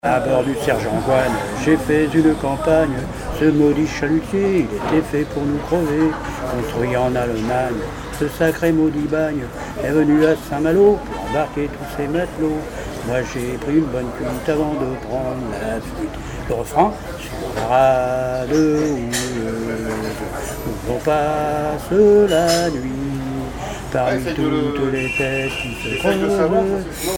Activités de marin pêcheurs sur les chalutiers et des chansons
Pièce musicale inédite